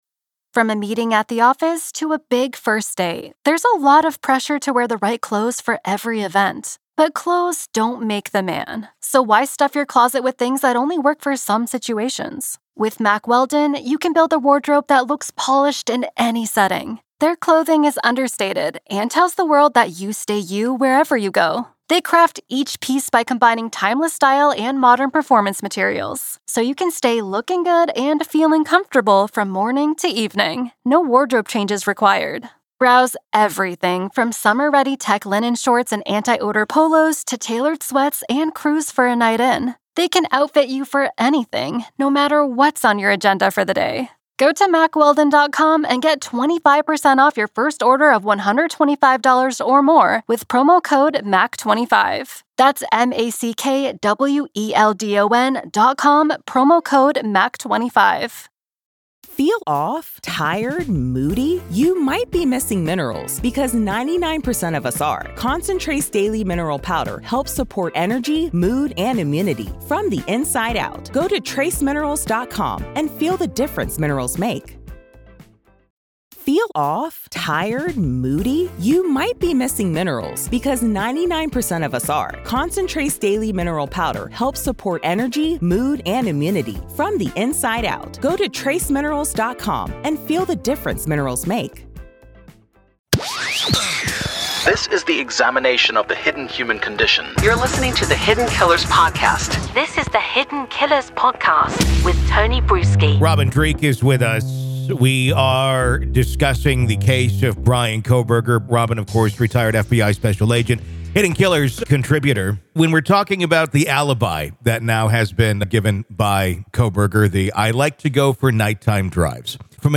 the conversation shed light on the intricacies of criminal behavior. Kohberger's defense claims that he has an affinity for nighttime drives, an alibi that may initially sound benign.